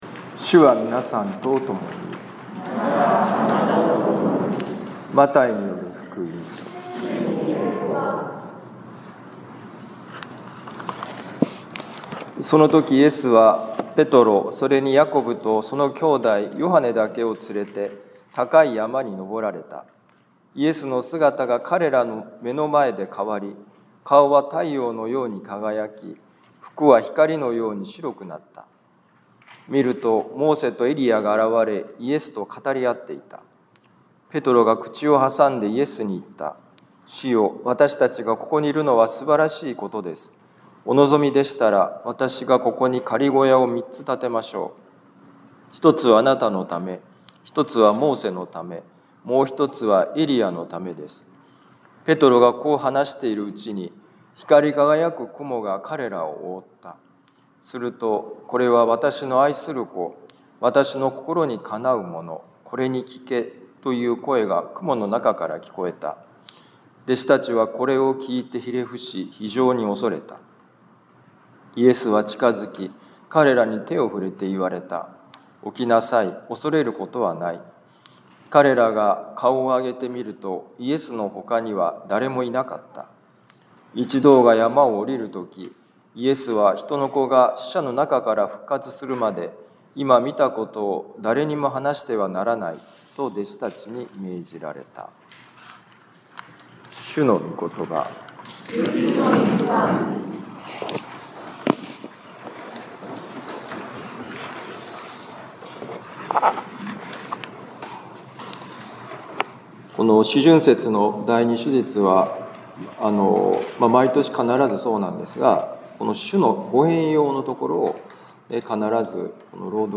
マタイ福音書17章1-9節「神の声に耳を澄まそう」2026年3月1日四旬節第２主日ミサ防府カトリック教会